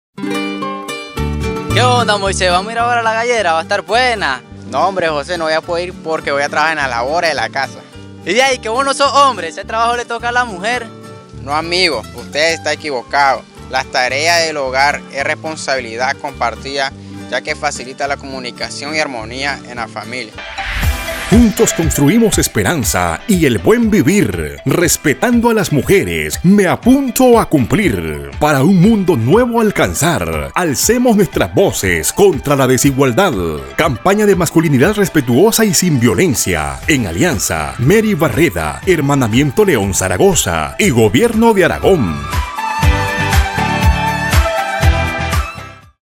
Importante destacar la participación protagónica de hombres de las comunidades del sector rural noreste de León.